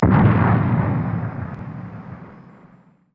hcannon-turret-fire.wav